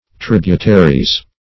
Search Result for " tributaries" : The Collaborative International Dictionary of English v.0.48: Tributary \Trib"u*ta*ry\, n.; pl. Tributaries . 1.